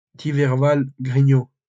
Thiverval-Grignon (French pronunciation: [tivɛʁval ɡʁiɲɔ̃]